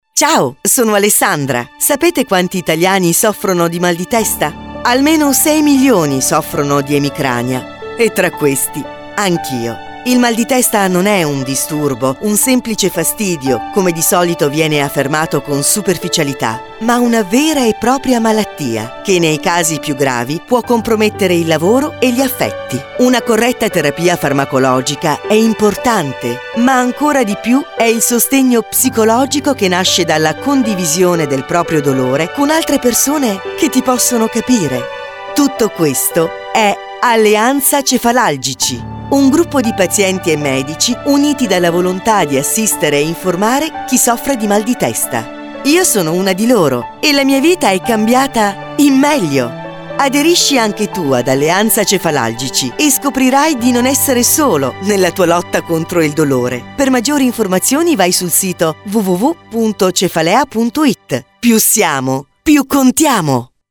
Spot radiofonico di Alleanza Cefalalgici